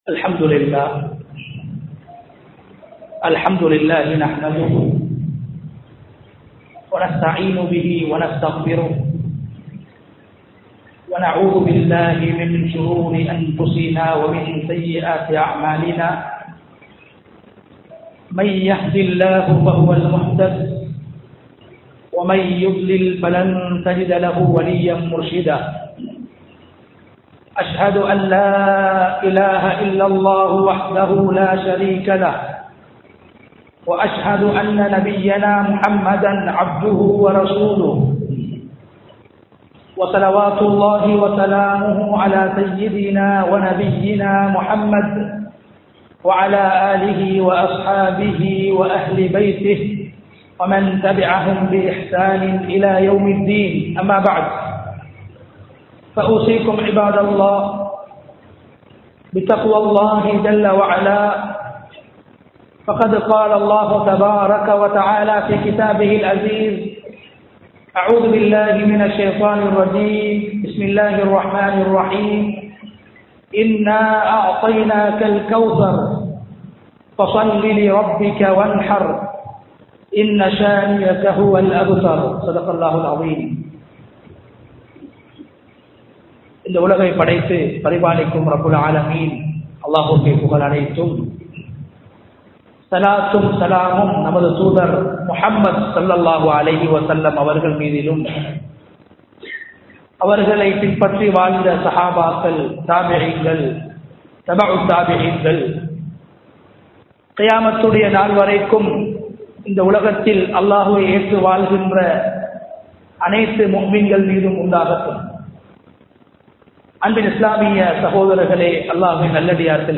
சூரா அல் கவ்ஸர் | Audio Bayans | All Ceylon Muslim Youth Community | Addalaichenai
Avissawella, Town Jumuah Masjith 2022-09-30 Tamil Download